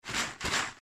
翻快递.mp3